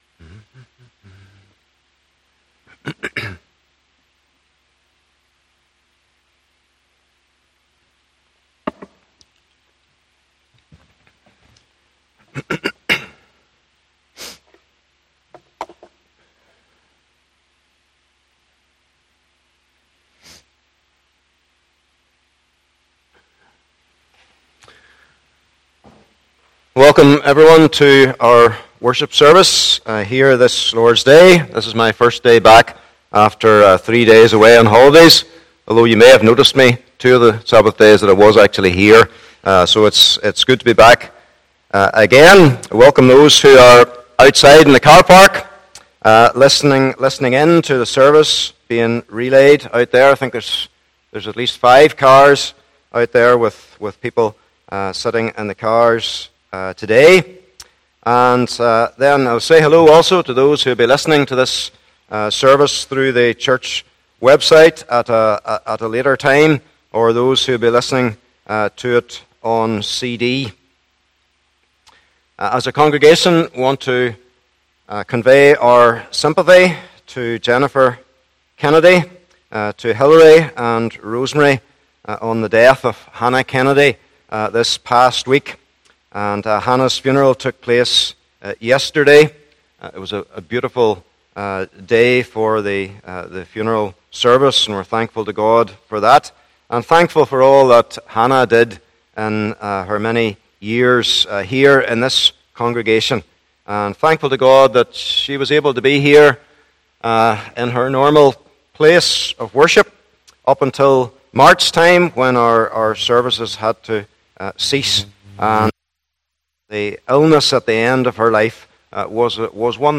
30 Service Type: Morning Service Bible Text